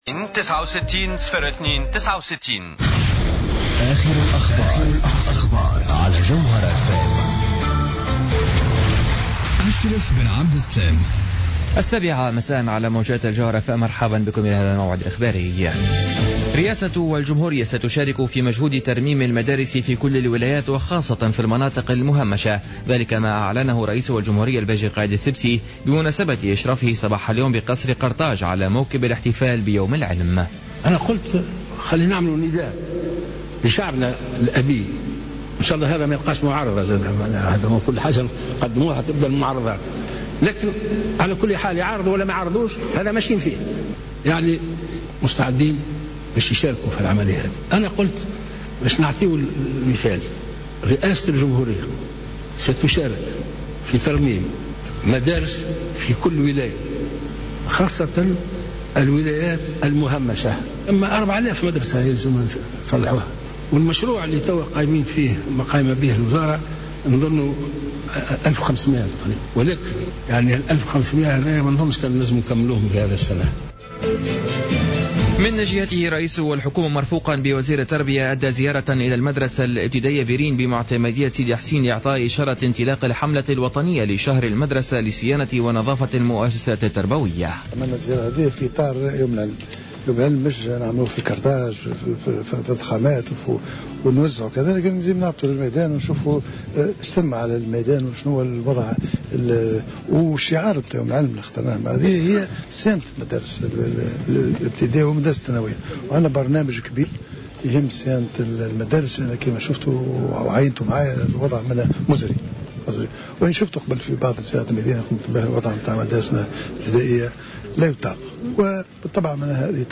نشرة أخبار السابعة مساء ليوم الأربعاء 22 جويلية 2015